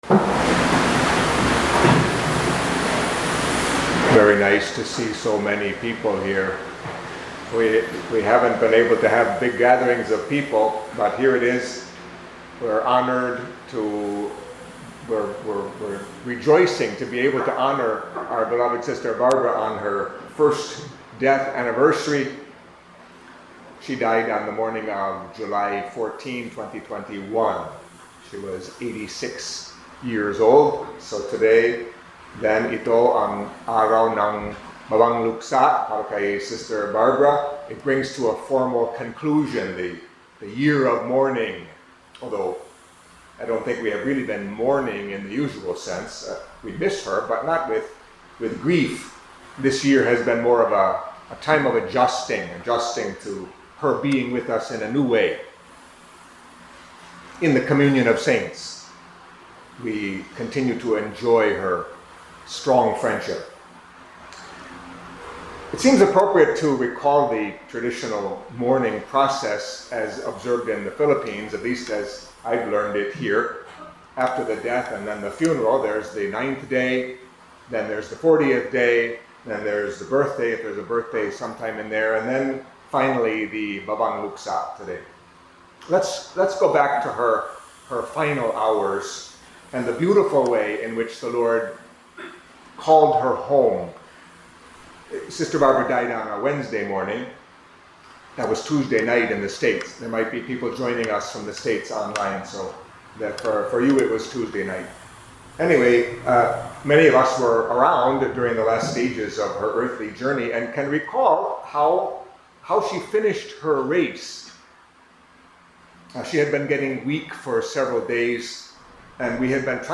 Catholic Mass homily for Thursday of the Fifteenth Week of Ordinary Time